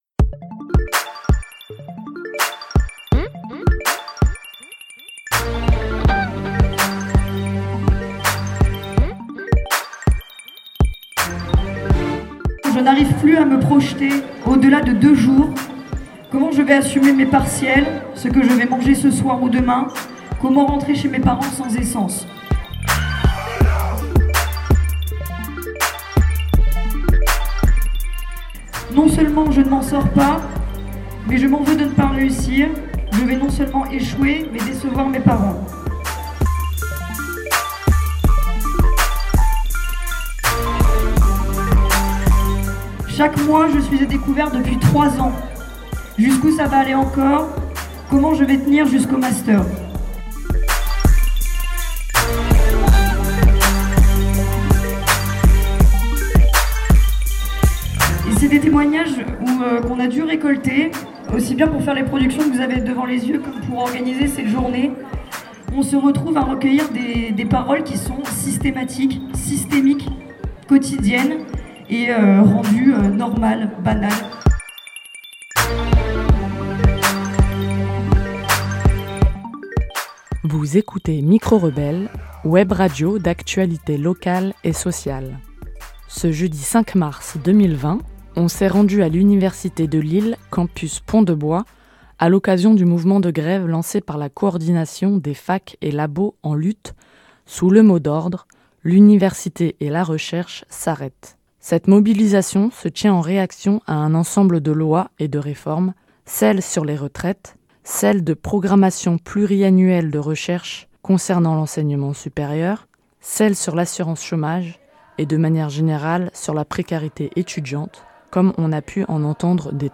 Pour l’occasion nous nous sommes rendu à l’université de Lille campus Pont de bois